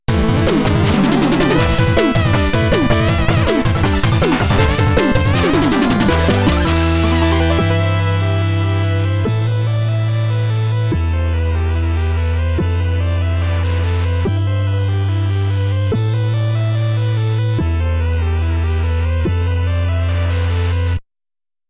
2A03 Classical